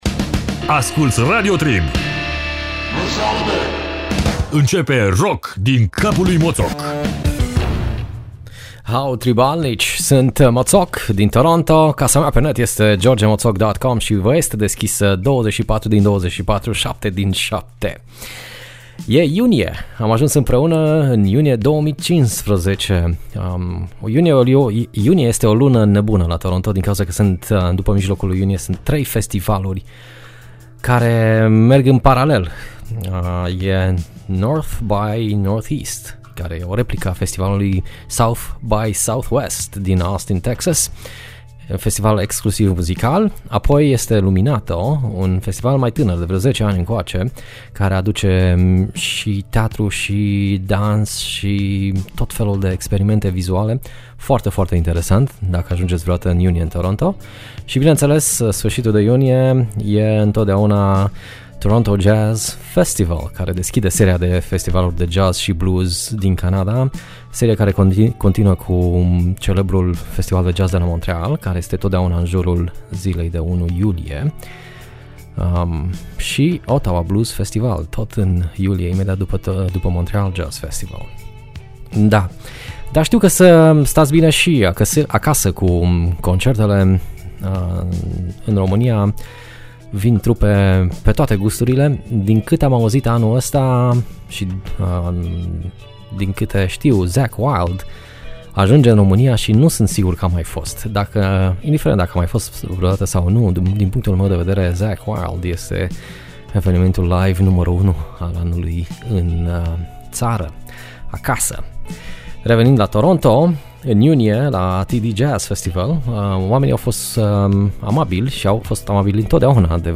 la modul cel mai delicat: cu niste flagioleti.